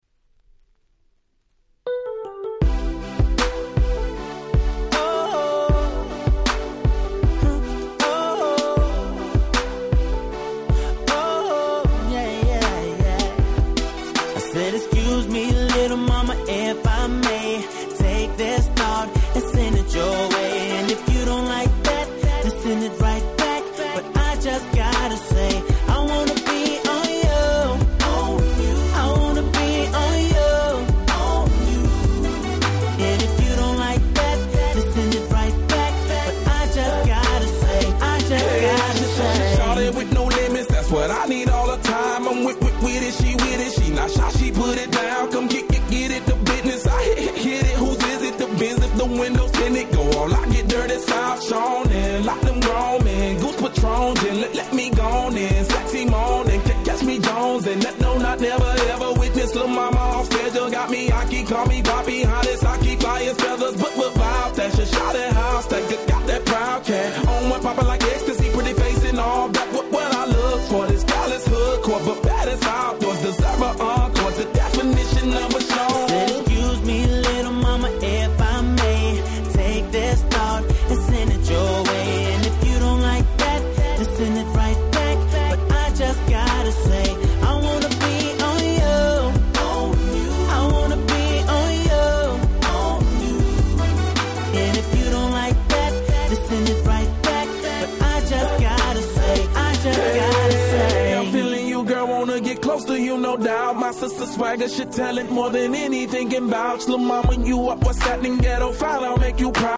09' Smash Hit Hip Hop/Pop !!